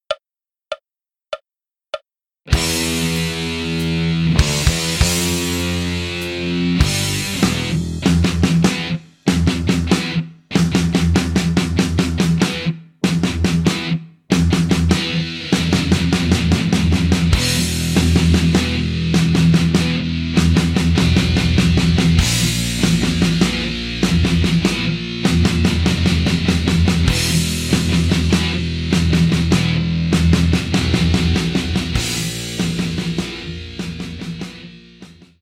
Guitare Basse Tablatures